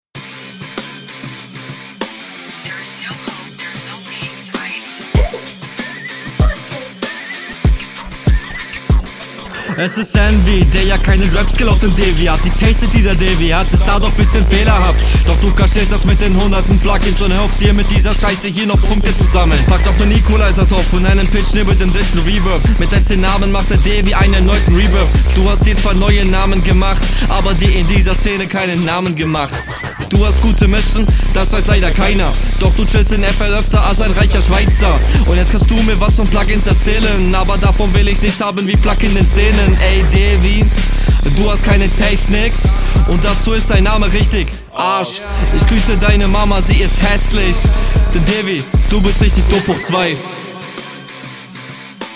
Beat hab ich schon so oft gehört das ich langsam psychische schäden erleide
Cooler Beat